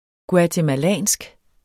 guatemalansk adjektiv Bøjning -, -e Udtale [ guatəmaˈlæˀnsg ] Betydninger fra Guatemala; vedr. Guatemala eller guatemalanerne